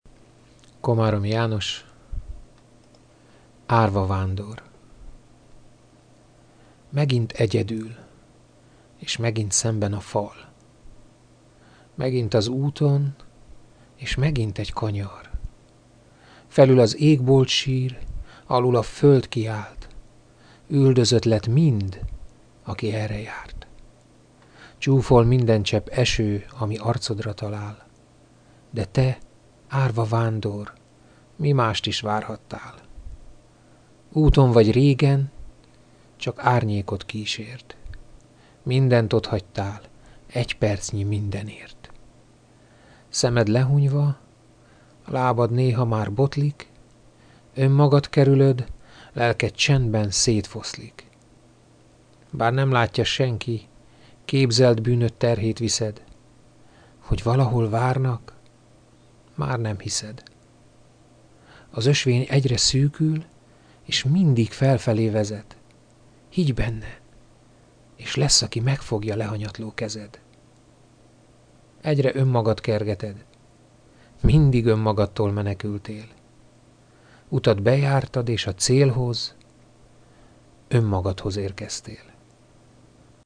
Egyszer azonban gondoltam egyet és elmondtam néhány versemet.